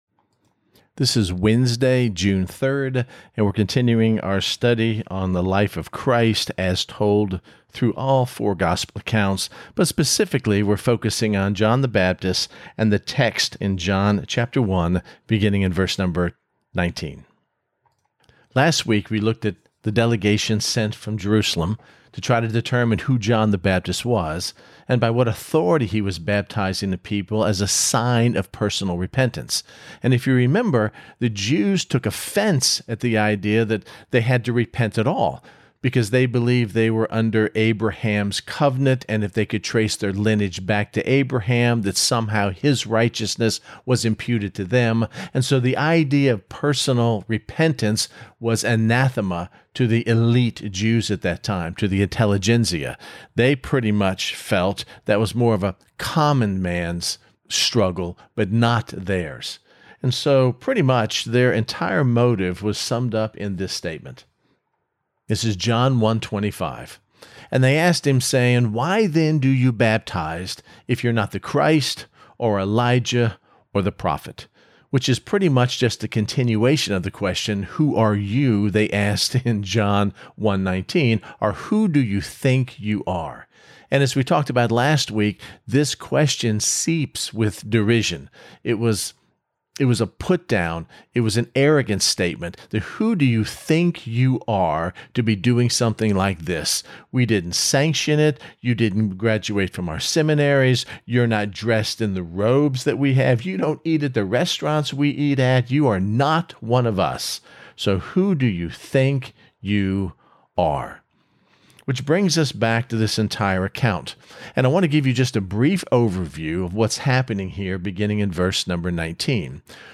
This is Part One of this week’s Tuesday Night Bible Study, and today, we will once again look at the character trait of humility that John the Baptist exhibited when he chose not to defend himself against the accusations of the Jerusalem delegation but pointed the conversation back to Jesus.